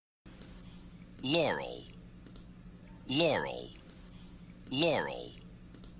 التركيز على الترددات العالية تجعل الناس يسمعون "Yanny" .
بينما التركيز على الترددات المنخفضة تجعل بعض الناس مثلي يسمعون كلمة "Laurel" .